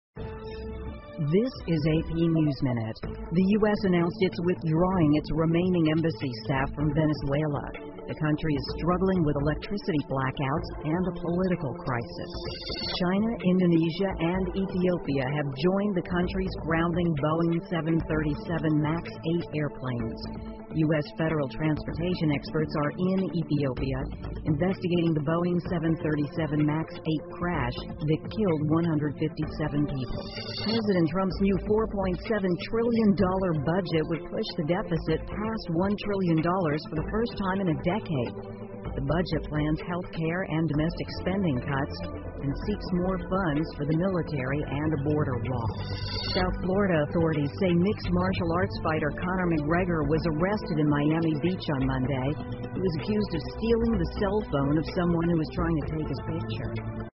美联社新闻一分钟 AP 多国停飞波音737 Max 8飞机 听力文件下载—在线英语听力室